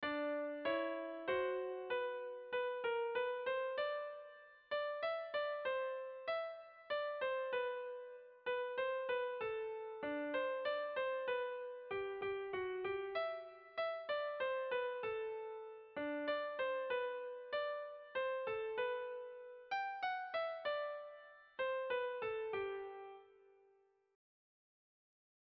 Irrizkoa
Kopla handia
ABD